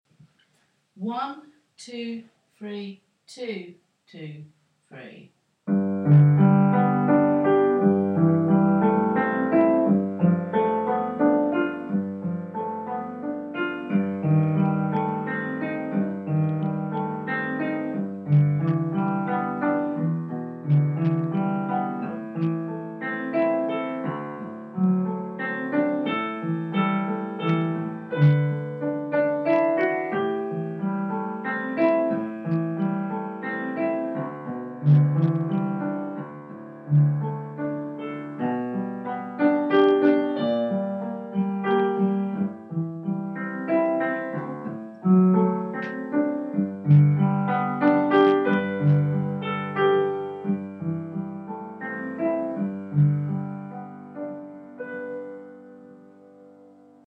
Pendulum piano part